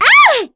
Cecilia-Vox_Damage_03.wav